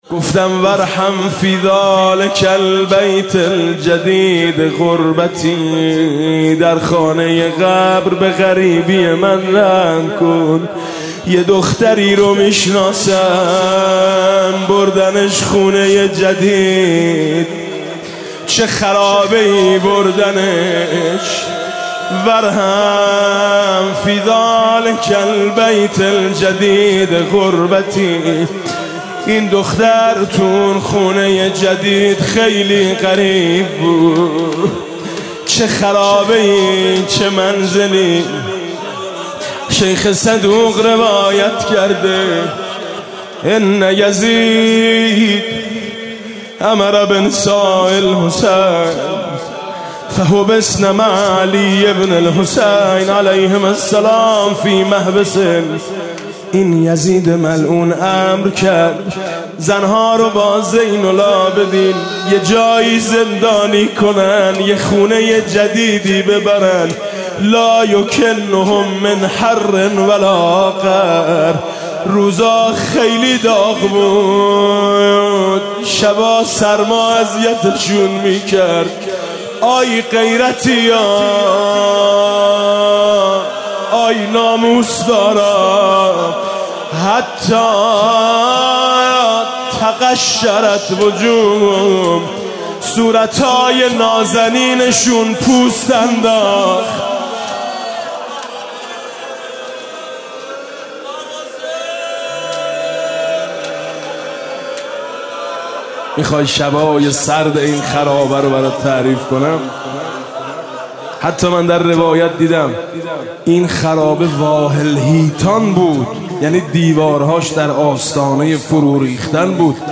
«شب هجدهم» روضه «حضرت رقیه (ع)»: بابا، دیگه منو تنها نگذار